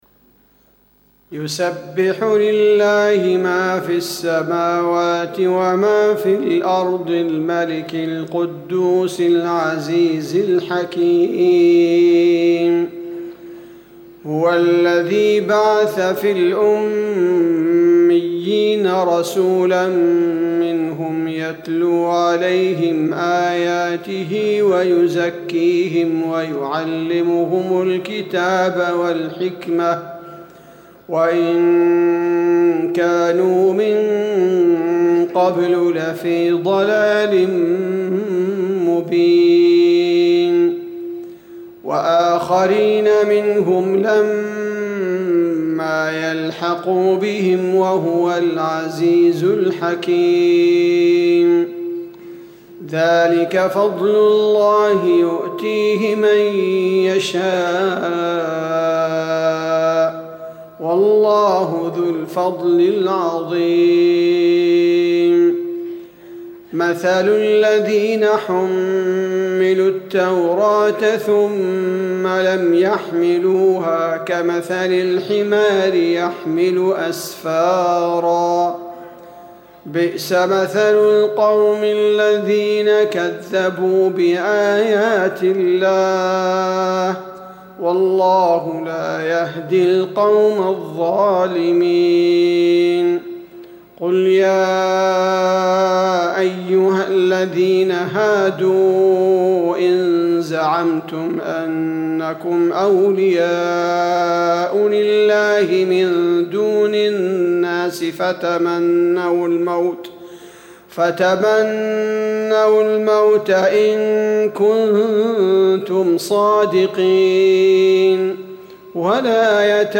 صلاة الفجر2-1-1440 سورة الجمعة Fajr prayer from Surat Al-Jumu'a > 1440 🕌 > الفروض - تلاوات الحرمين